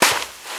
High Quality Footsteps
STEPS Sand, Walk 20.wav